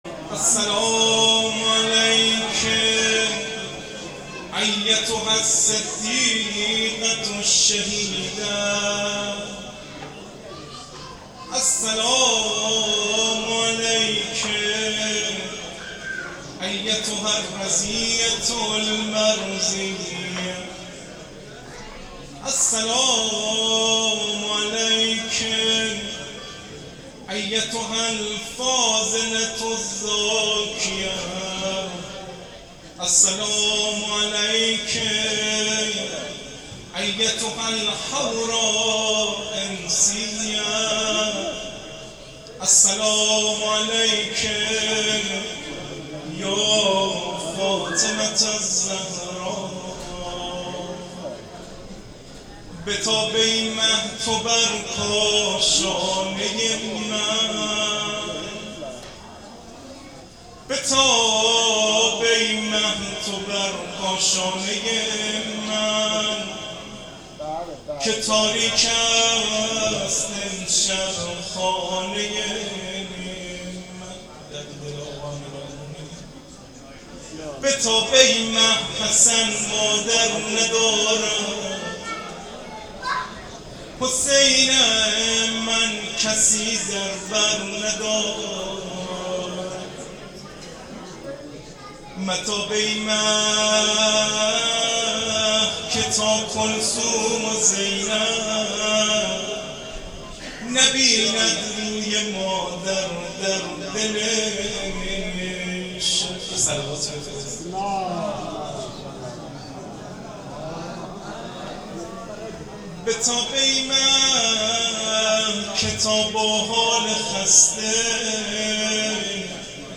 روضه شام غریبان حضرت زهرا س ،